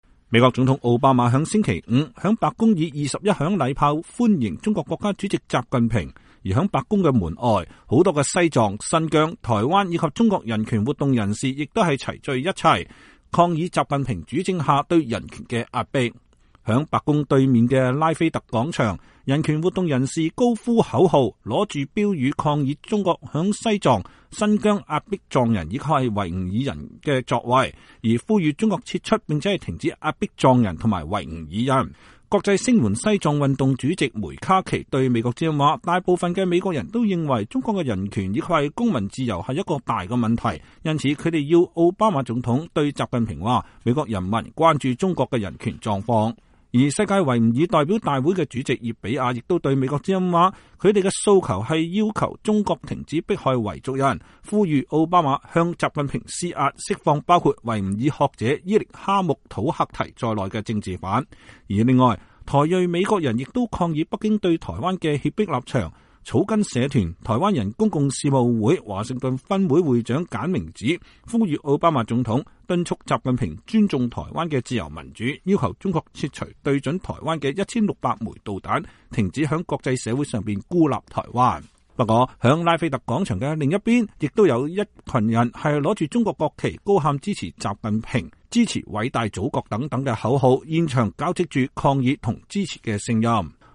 在白宮對面的拉斐特廣場，人權活動人士高呼口號，拿著標語抗議中國在西藏、新疆壓迫藏人和維吾爾人的作為，呼籲中國撤出並停止壓迫藏人及維吾爾人。
在拉斐特廣場另一頭，也有一群人拿著中國國旗，高喊支持習近平、支持“偉大的祖國”口號，現場交織著抗議與支持的聲音。